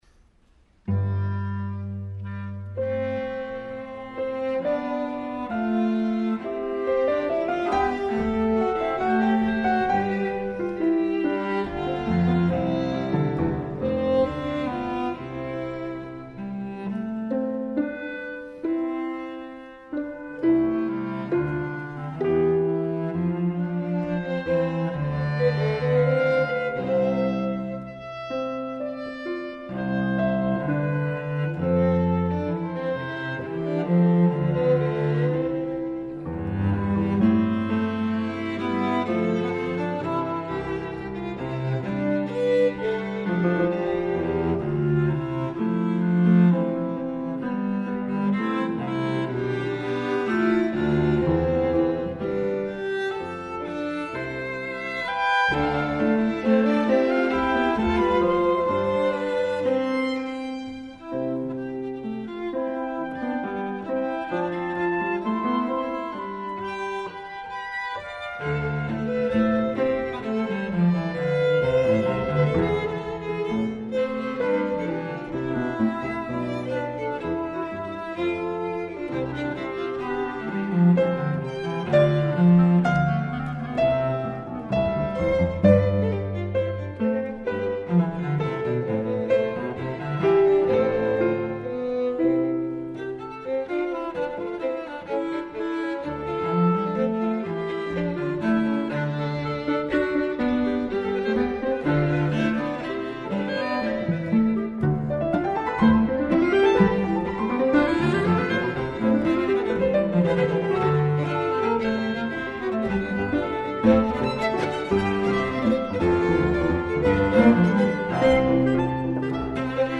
chamber work